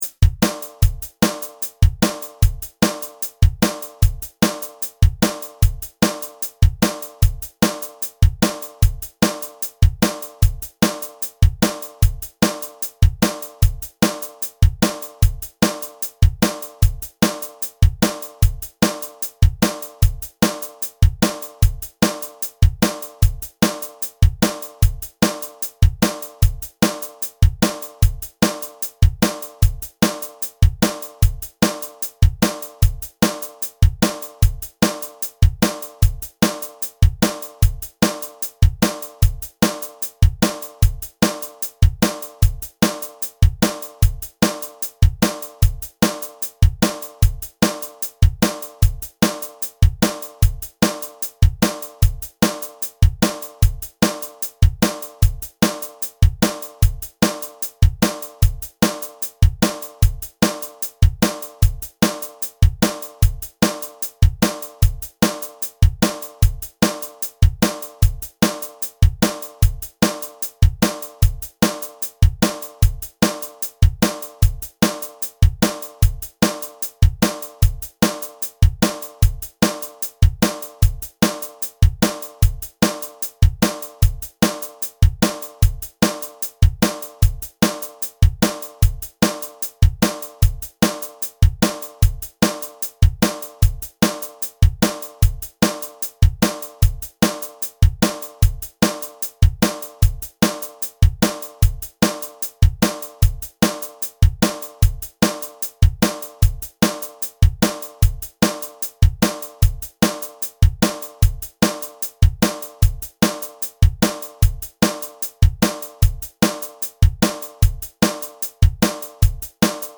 Scale – G Major Pentatonic